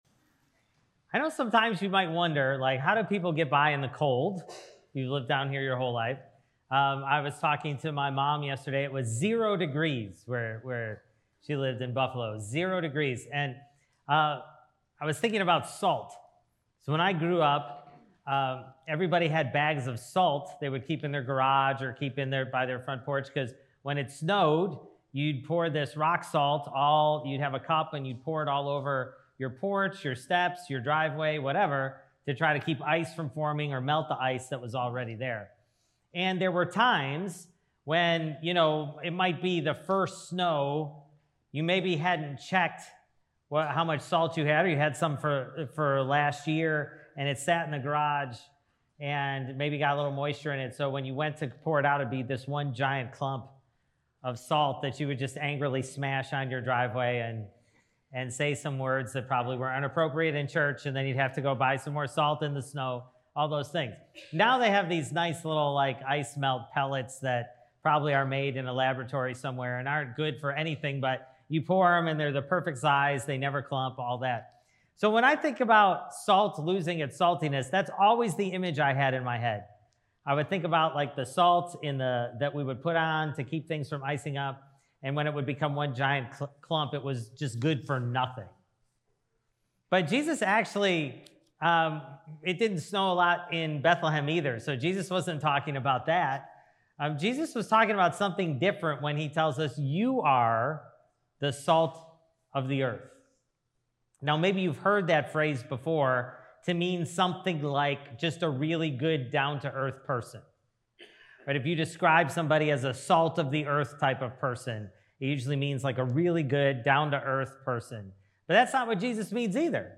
Sermon: Salt of the Earth (Matthew 5:13-20)